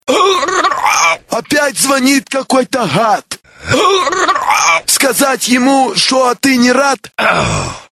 Главная » Рингтоны » Рингтоны пародии